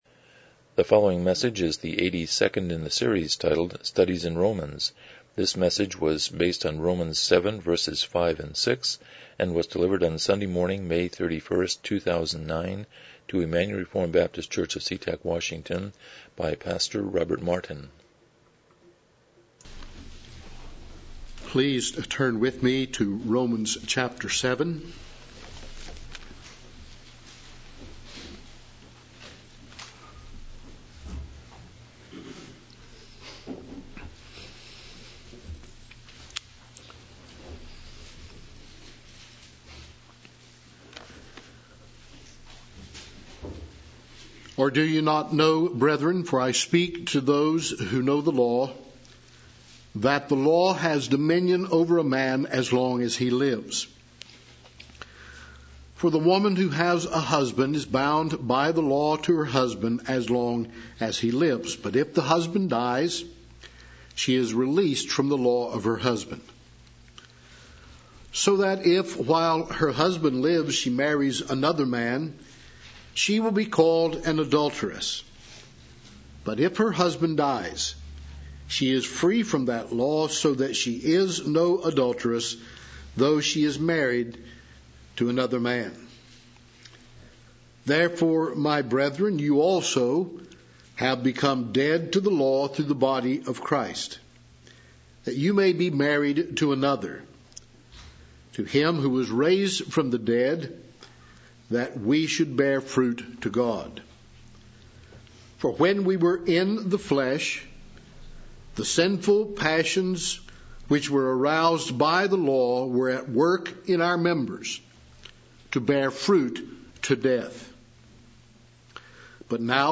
Romans 7:5-6 Service Type: Morning Worship « 34 The Abrahamic Covenant